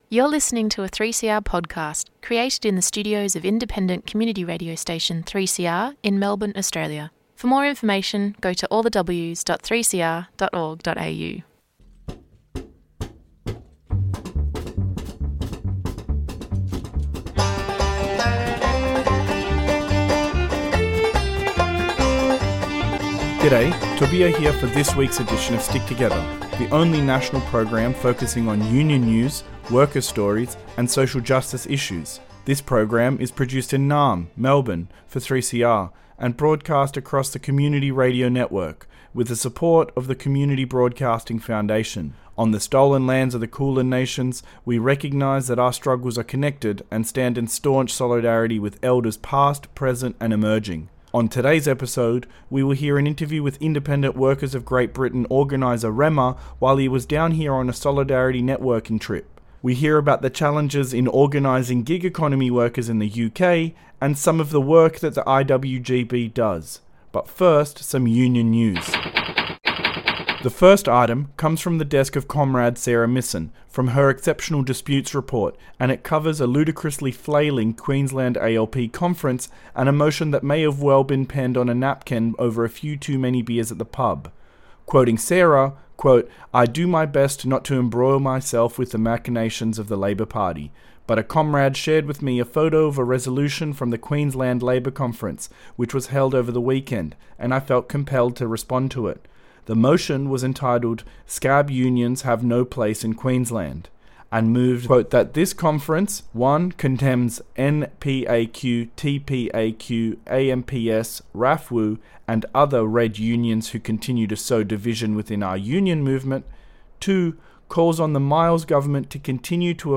An interview with IWGB